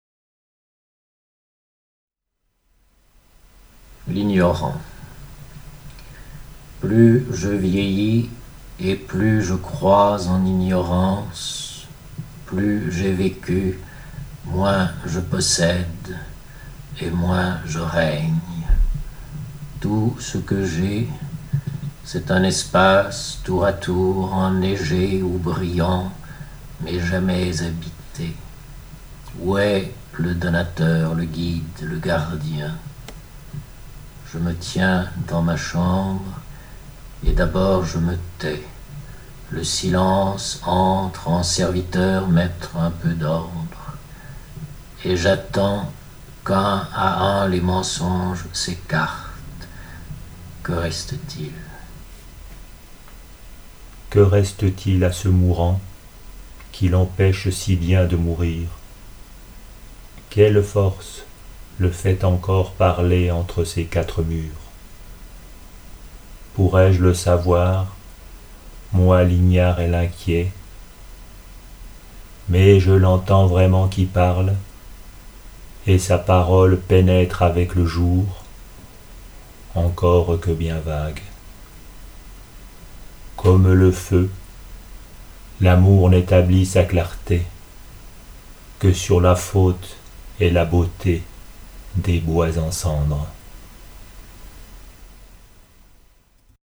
Ai trouvé sur le WEB, chemin faisant, les premiers vers de L’Ignorant, lus par l’auteur, voix d’ailleurs et d’un autre temps. voix de revenant. Son poème se referme avant la fin, sur un silence dans lequel je glisse ma voix.